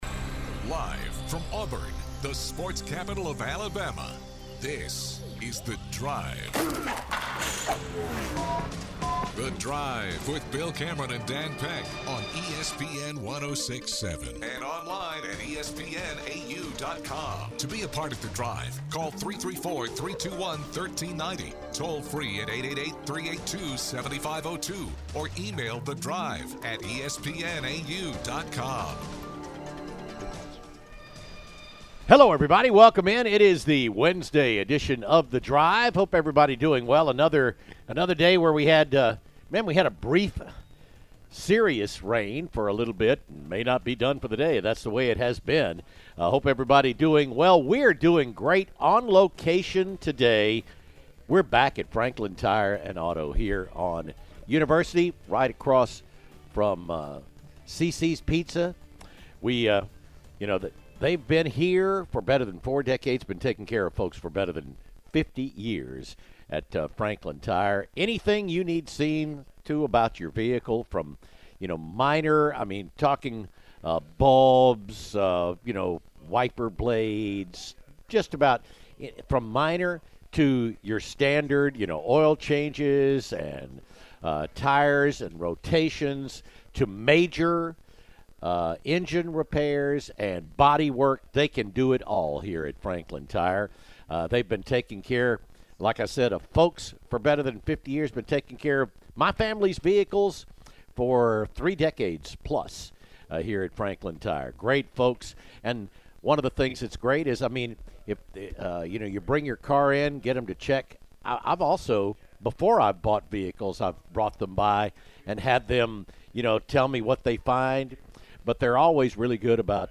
live from Franklin!